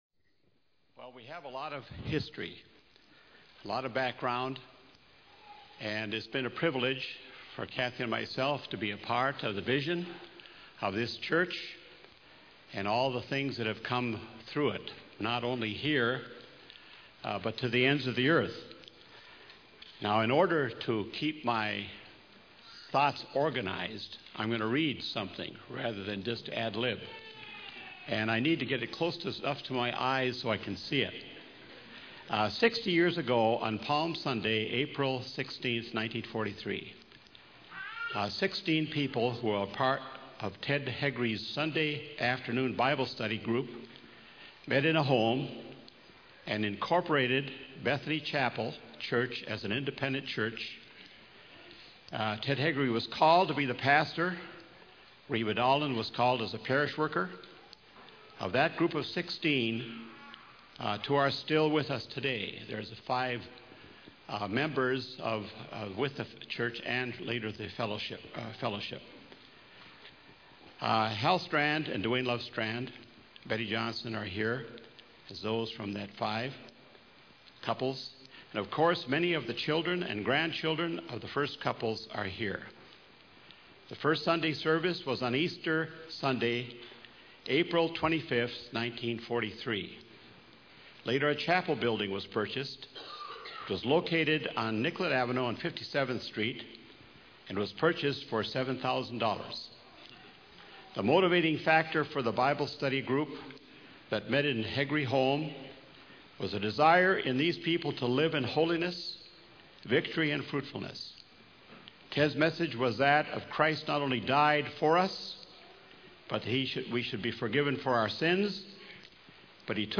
The sermon recounts the rich history and mission-driven legacy of Bethany Missionary Church, highlighting its humble beginnings and commitment to global missions.
Sermon Outline